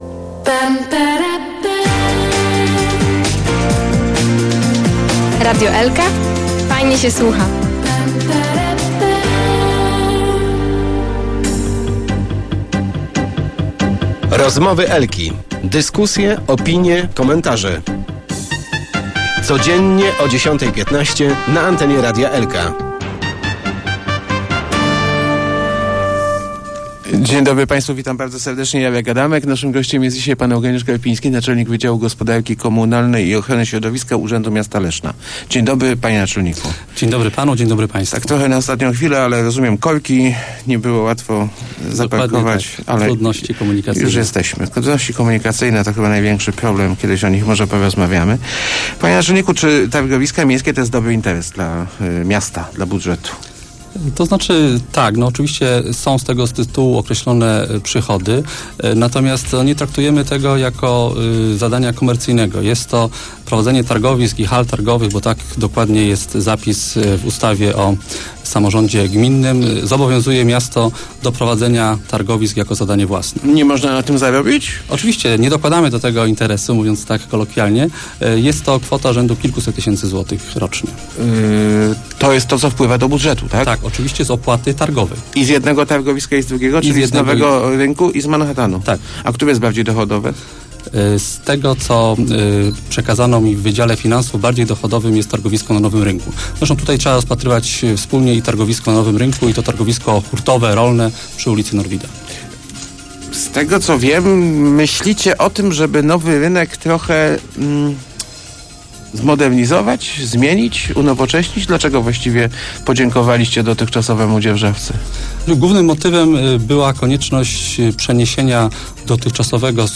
15.04.2009. Radio Elka